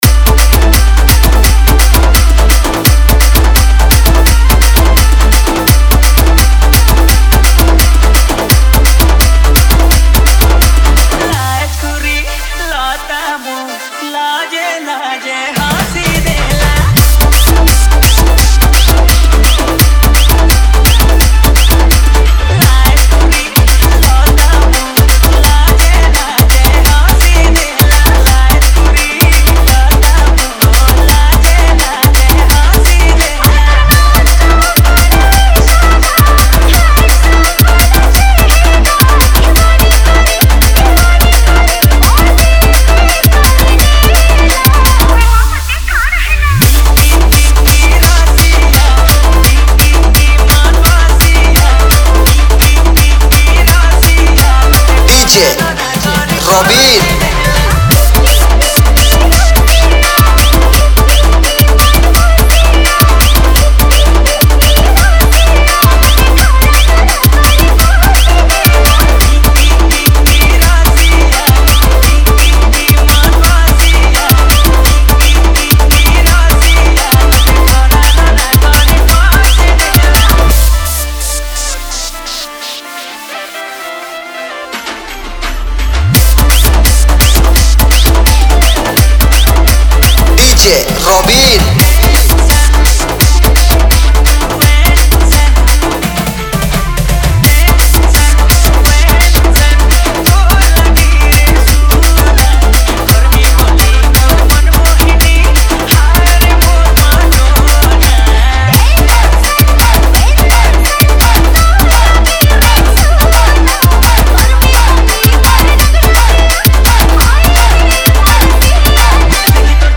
Category: New Sambalpuri Folk Dj Songs 2022